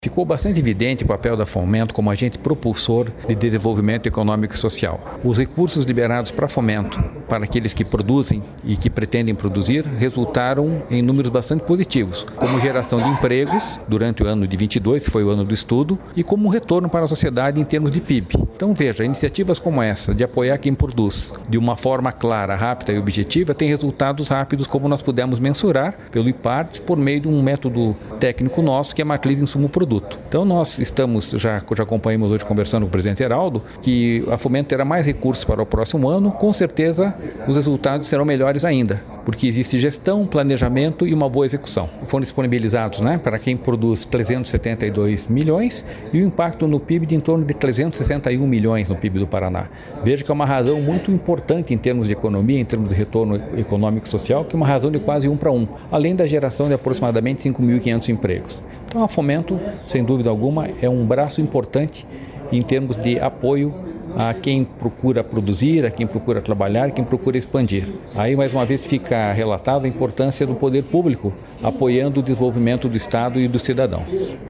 Sonora do diretor-presidente do Ipardes, Jorge Callado, sobre o estudo feito pelo órgão com os impactos do crédito disponibilizado pela Fomento Paraná